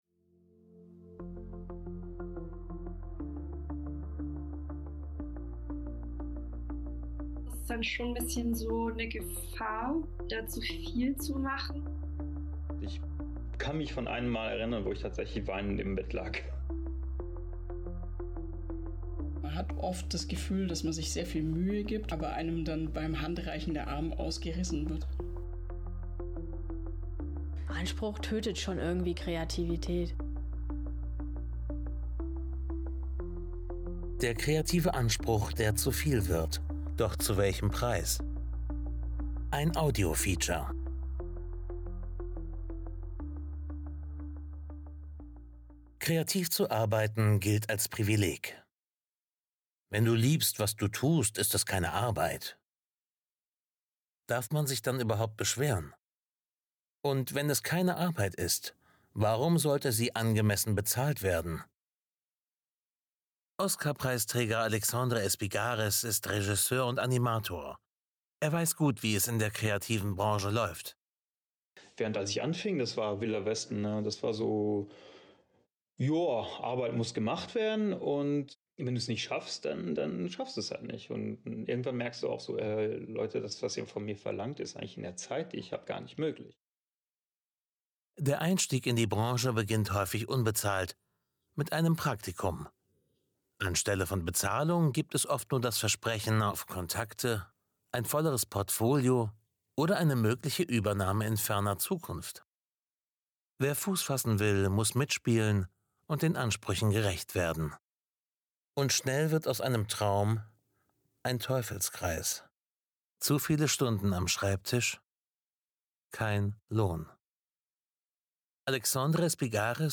Ein Audio-Feature.